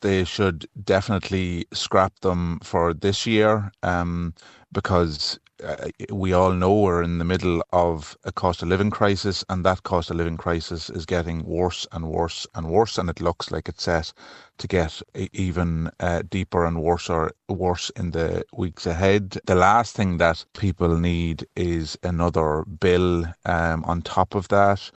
Sinn Fein TD for Meath East, Darren O’Rourke says there should be a U-turn on the decision, for this year at least: